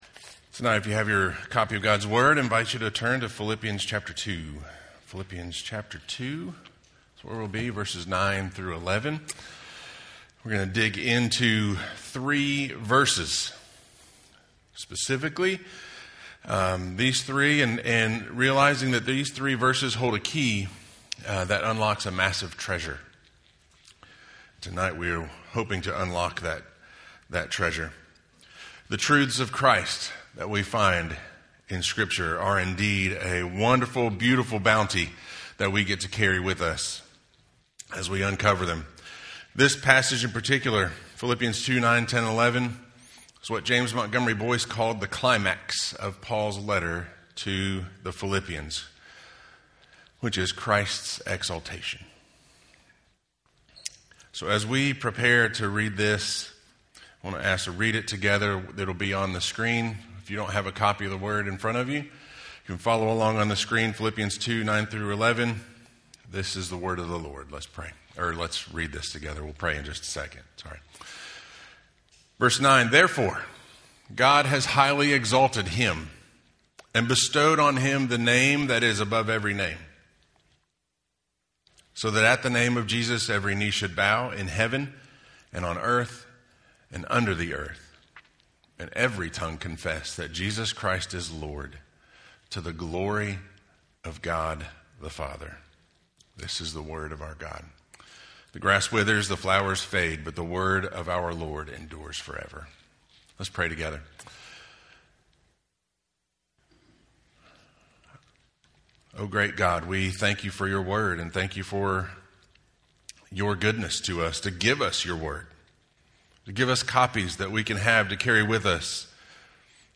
Philippians 2:9-11 Audio Sermon